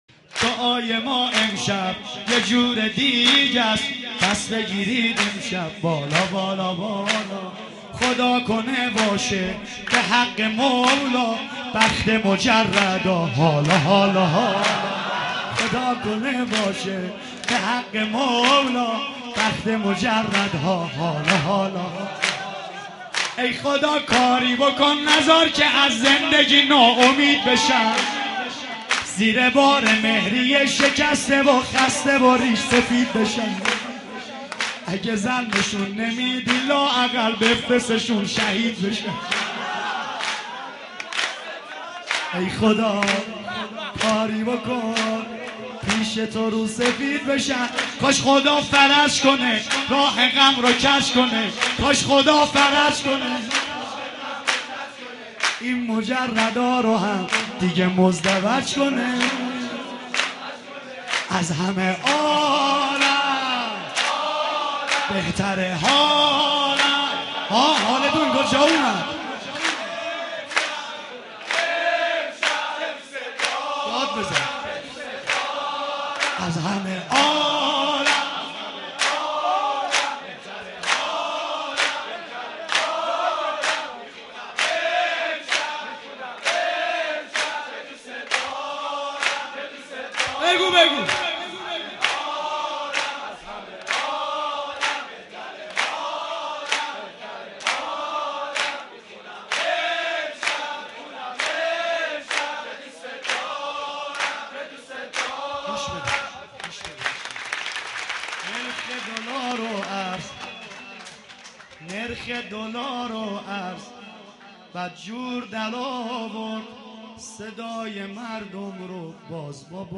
مداحی مجردها